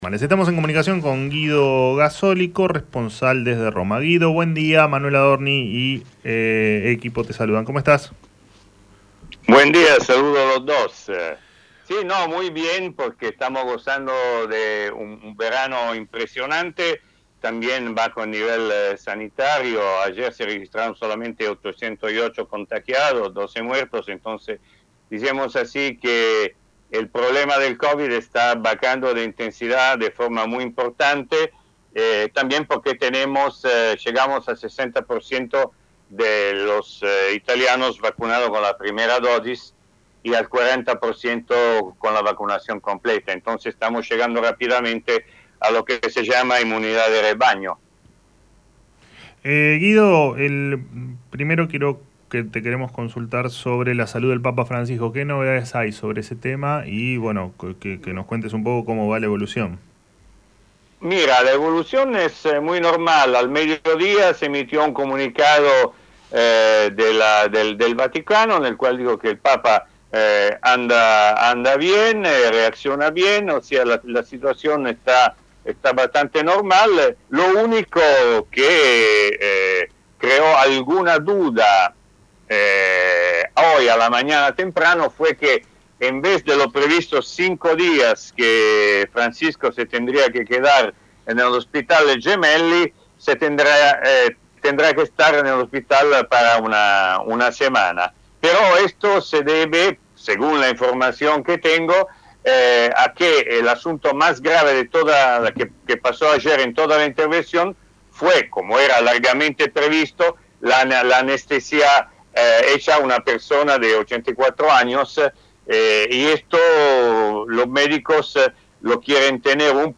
El periodista italiano